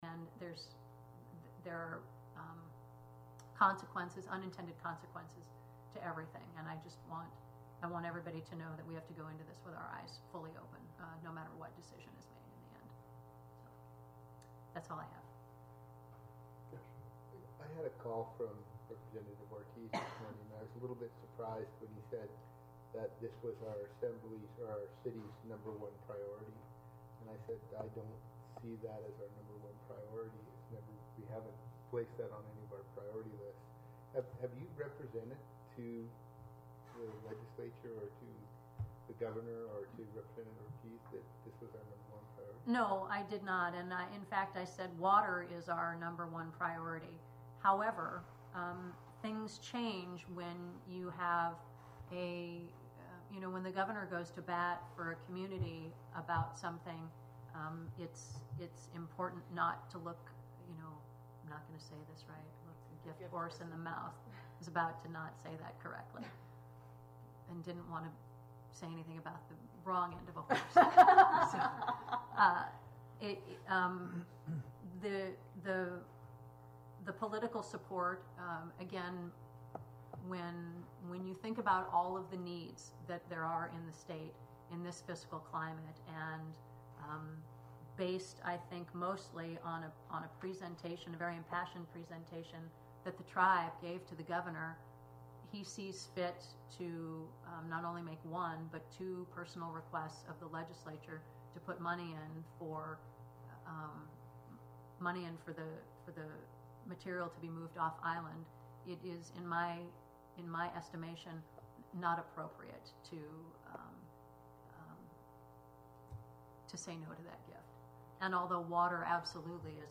Wrangell Borough Assembly met for a regular meeting on May 8, 2018.